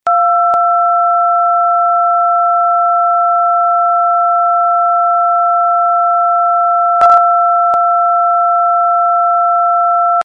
Sound effect
电报.mp3